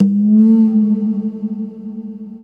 PERQ FX   -L.wav